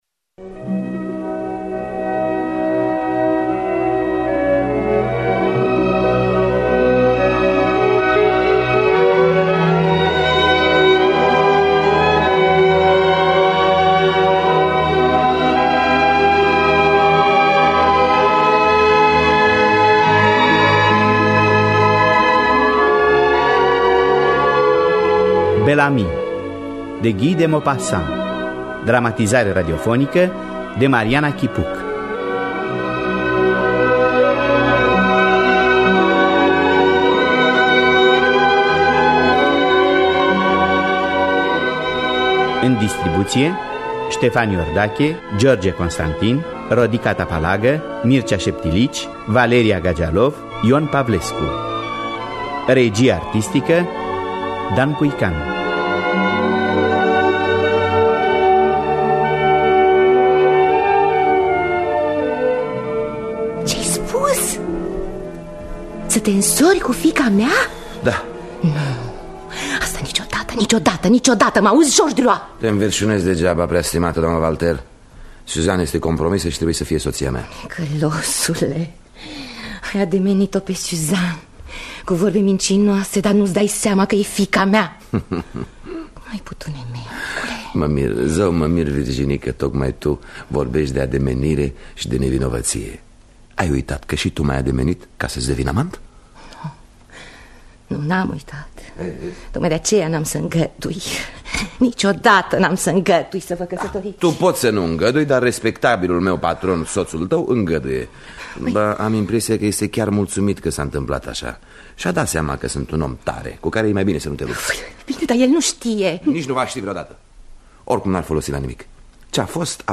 “Bel-Ami” de Guy de Maupassant – Teatru Radiofonic Online